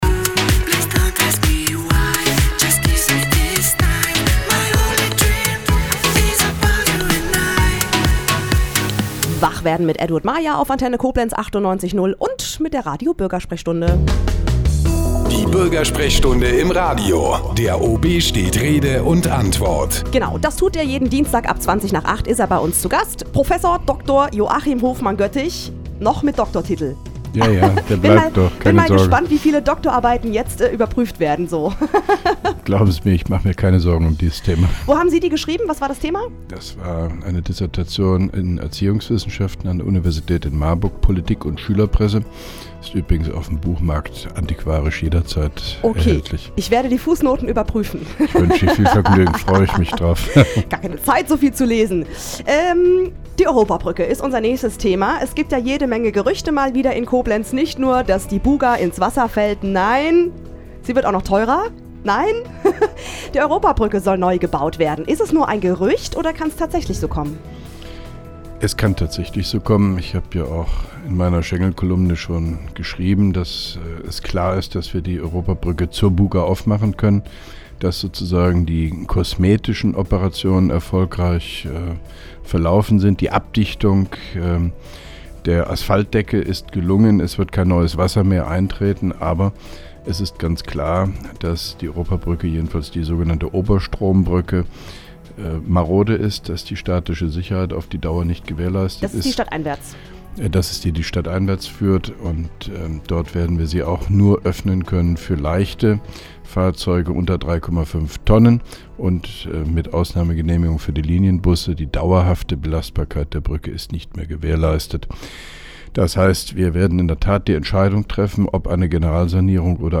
(3) Koblenzer Radio-Bürgersprechstunde mit OB Hofmann-Göttig 22.02.2011